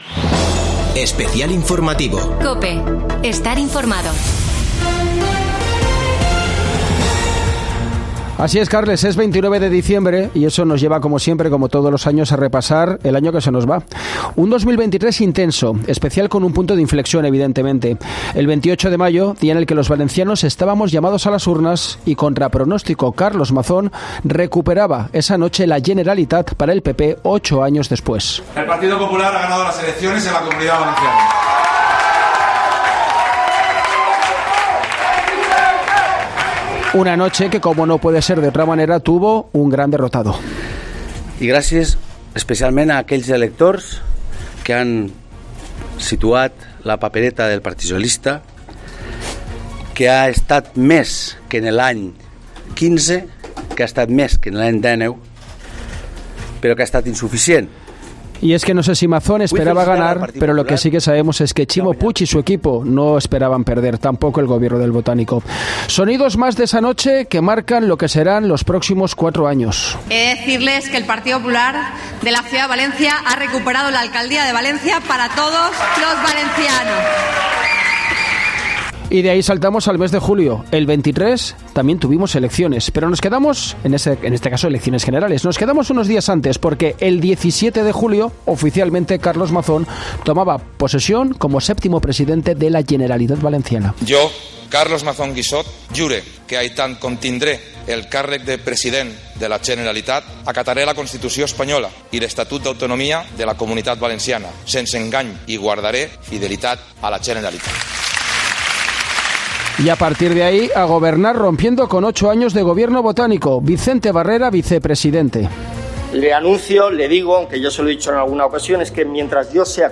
Estos son los sonidos que han marcado 2023 en la Comunitat Valenciana
Lo explicó en COPE y en el mes de septiembre el conseller de educación, José Antonio Rovira.